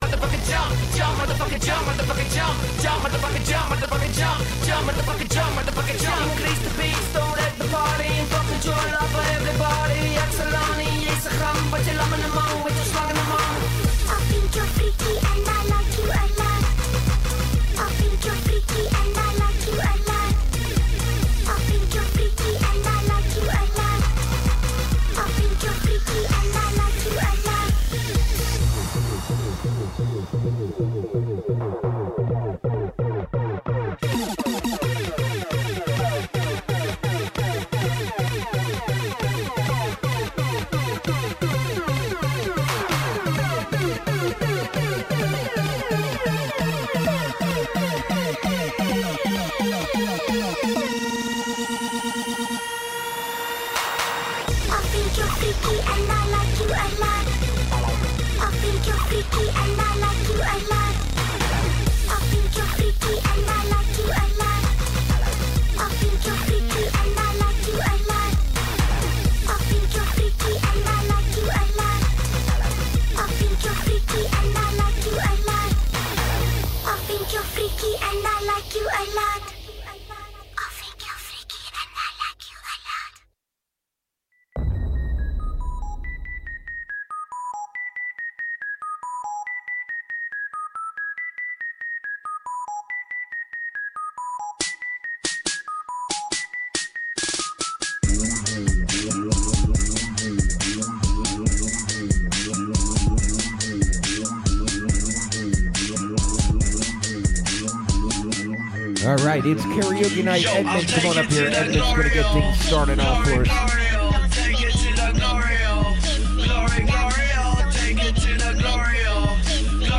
Live Wednesday through Saturday 9-1 with the Metro Best Karaoke with Mile High Karaoke on 03-Oct-25-21:03:46